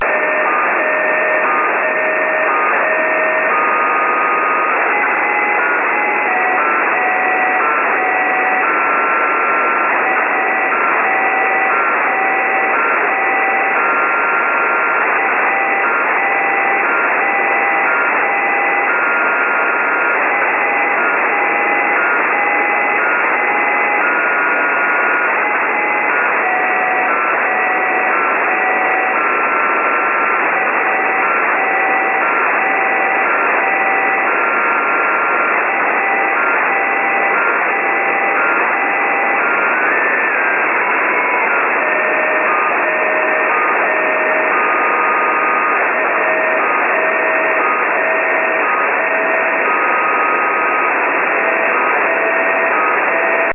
Due to a wrong setting of the mp3 recorder the first part of the recording is over-modulated.